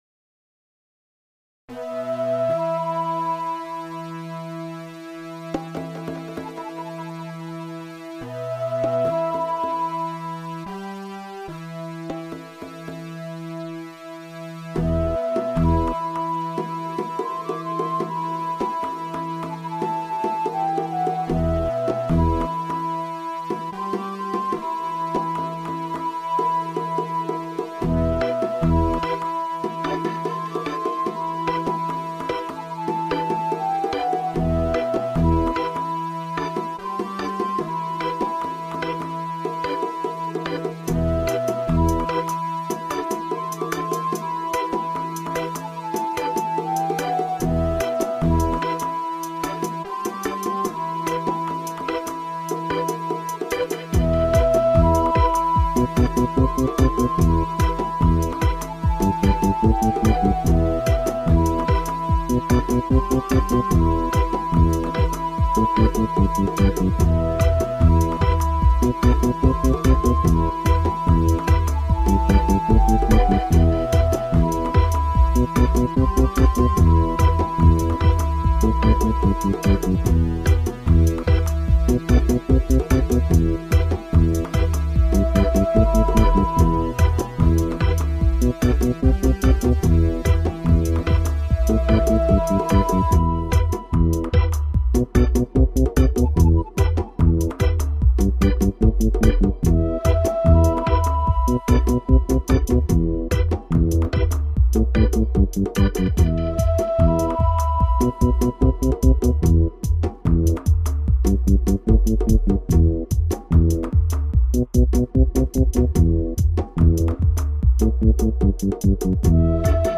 Big stepper Tune!!